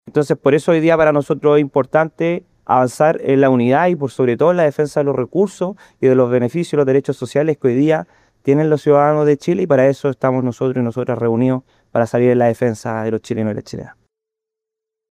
Por el lado de los alcaldes independientes, Matías Toledo, dijo que pese a las diferencias latentes entre la izquierda y el progresismo, el encuentro constituye la unidad necesaria que necesitan los vecinos y vecinas que esperan que ocurran cosas positivas para sus comunas y el país.